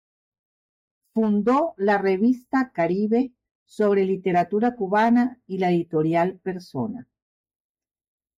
Hyphenated as e‧di‧to‧rial Pronounced as (IPA) /editoˈɾjal/